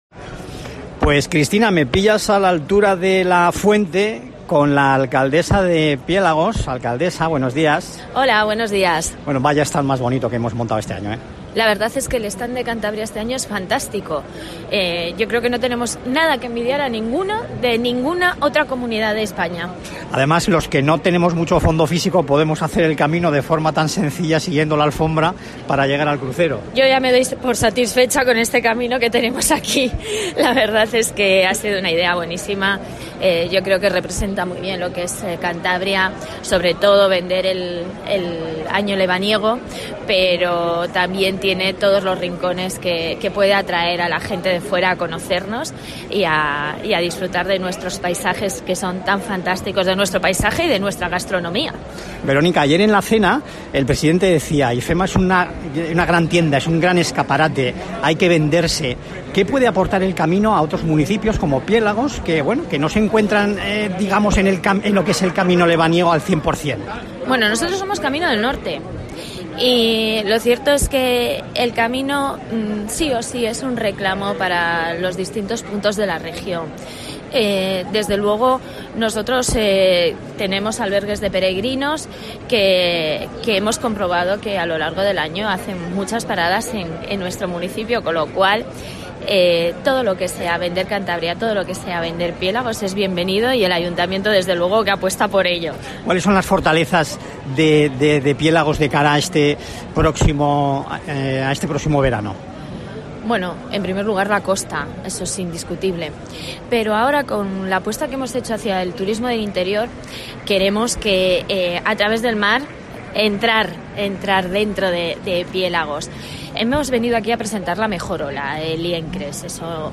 Entrevista alcaldesa de Piélagos en Fitur